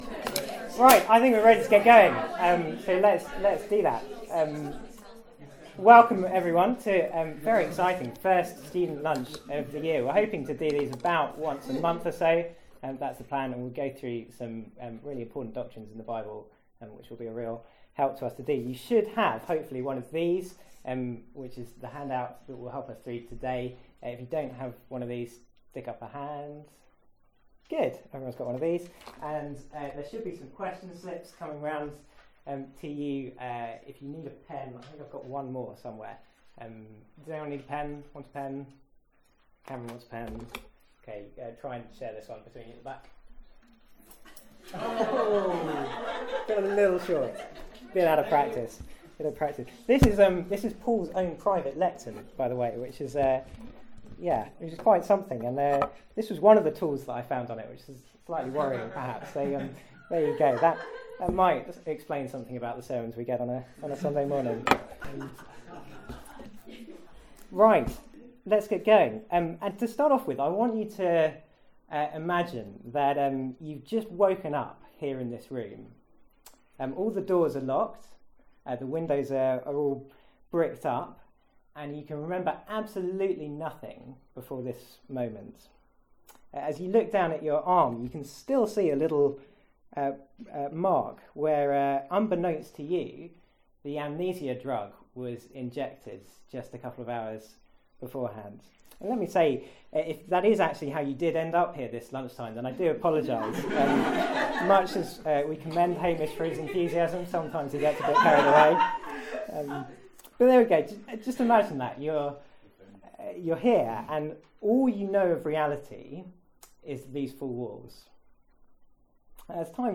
The talk from Student Lunch on 2nd Nov 2014.